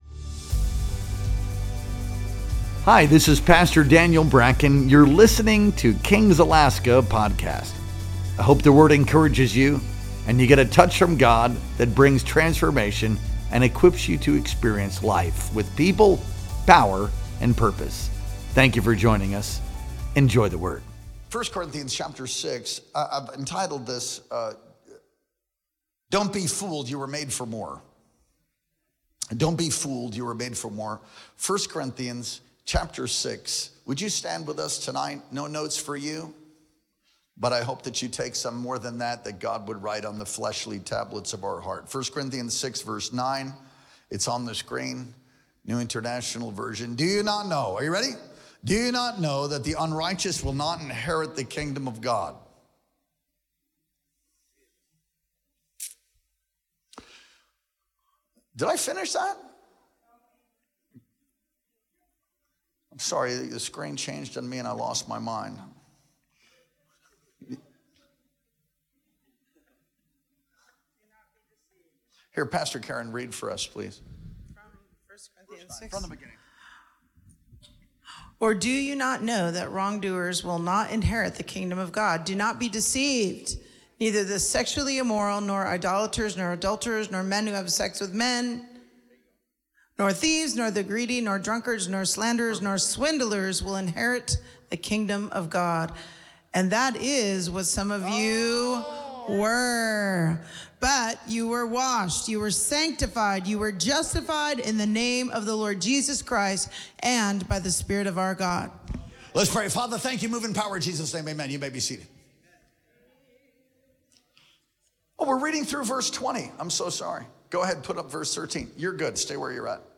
Our Midweek Worship Experience streamed live on July 30th, 2025.